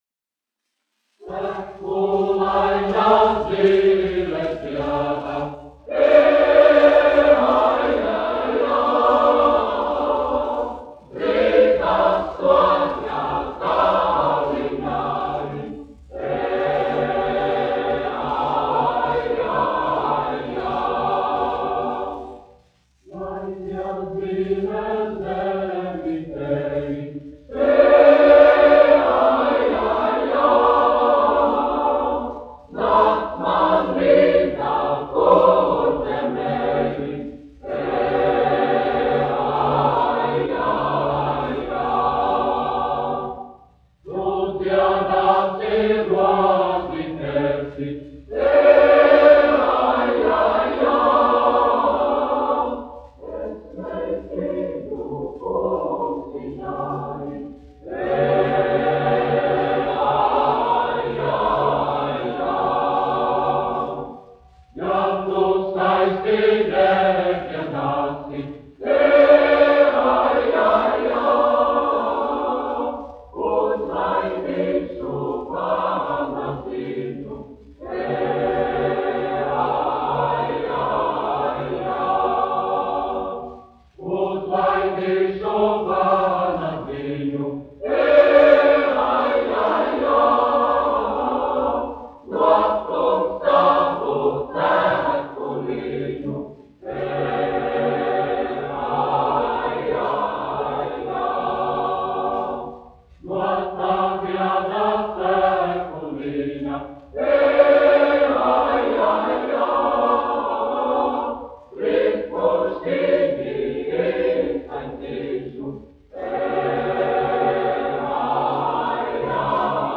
Daugava (koris : Geesthacht), izpildītājs
1 skpl. : analogs, 78 apgr/min, mono ; 25 cm
Latviešu tautasdziesmas
Kori (jauktie)
Skaņuplate